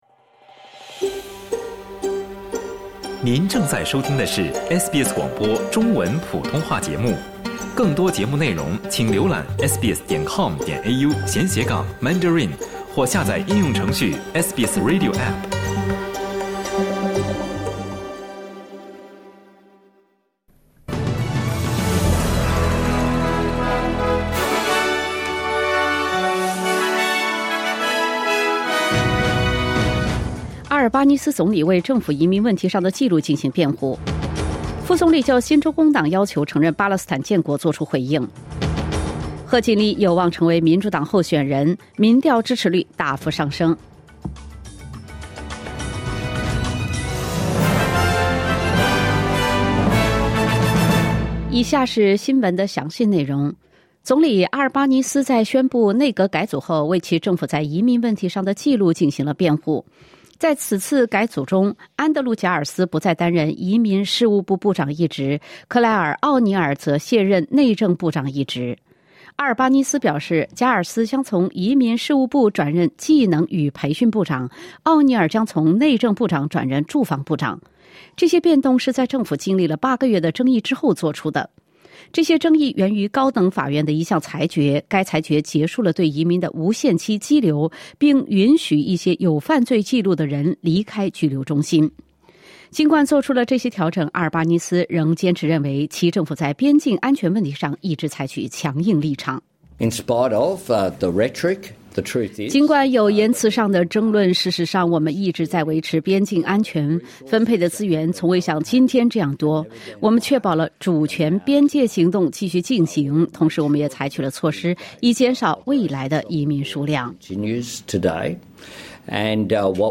SBS早新闻（2024年7月29日）